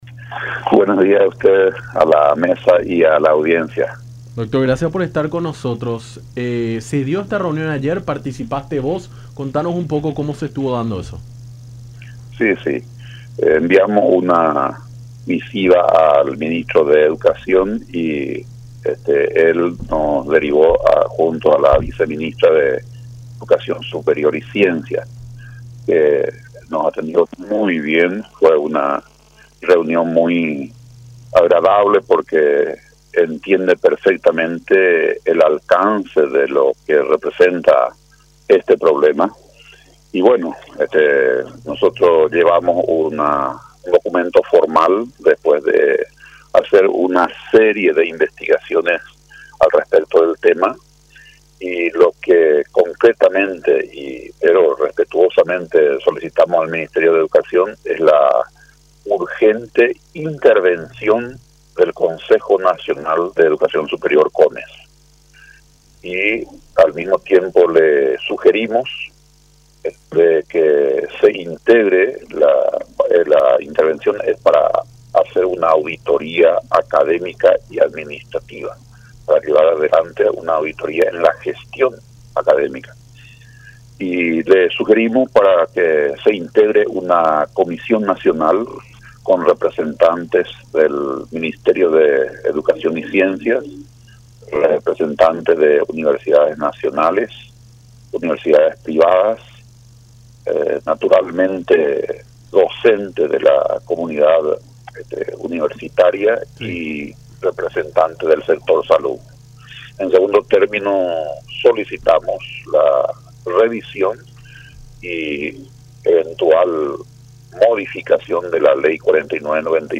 en diálogo con Nuestra Mañana por La Unión.